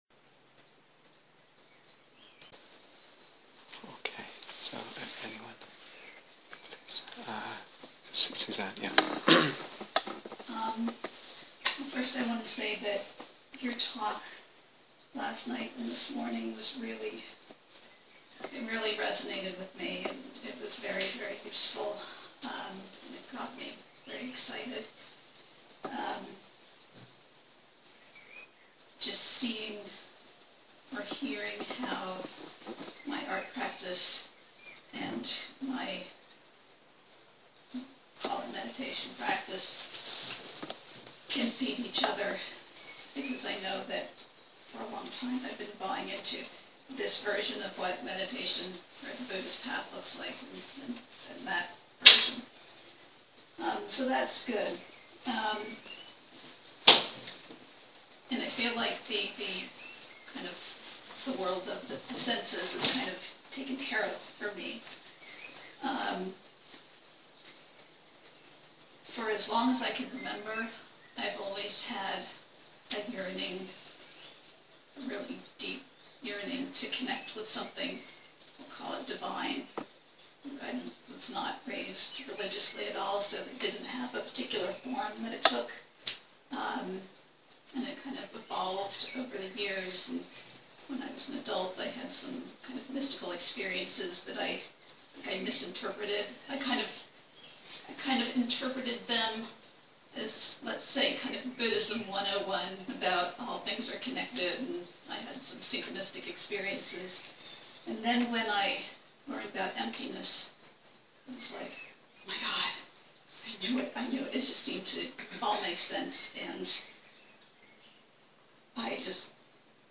Awakening and Soulmaking (Q & A)